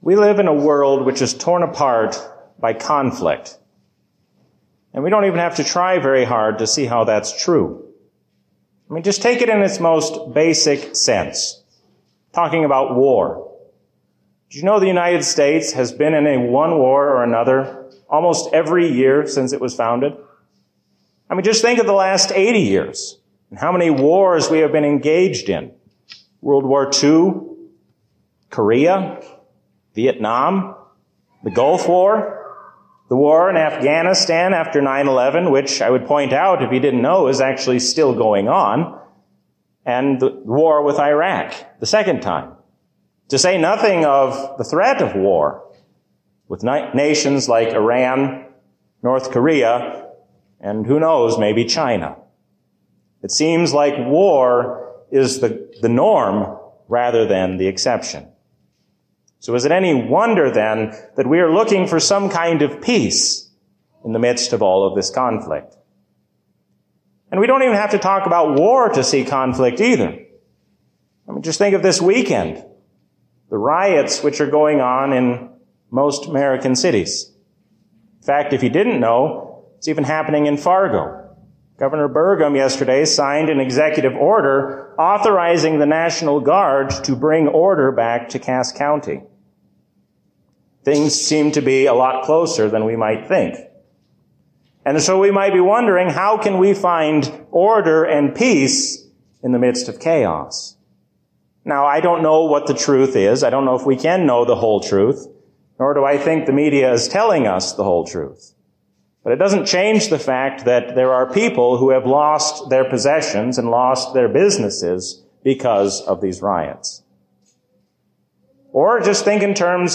Sermon
A sermon from the season "Pentecost 2020."